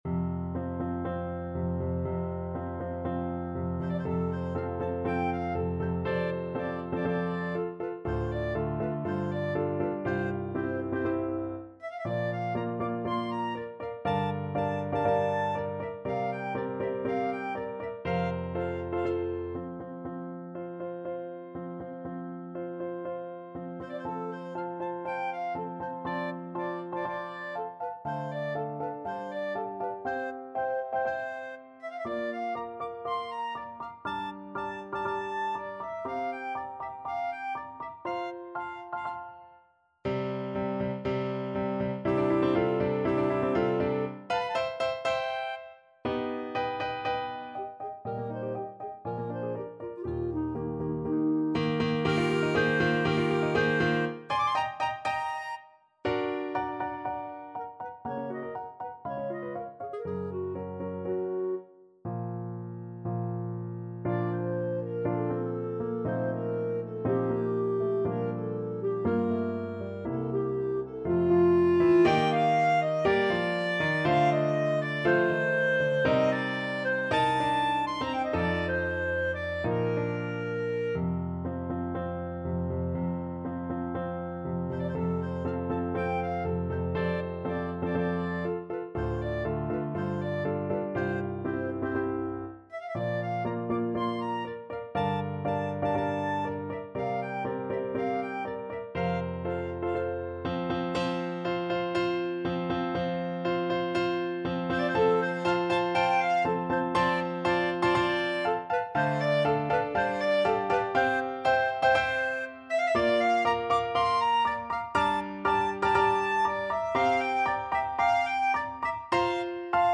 Clarinet
F major (Sounding Pitch) G major (Clarinet in Bb) (View more F major Music for Clarinet )
4/4 (View more 4/4 Music)
~ = 120 Tempo di Marcia un poco vivace
Classical (View more Classical Clarinet Music)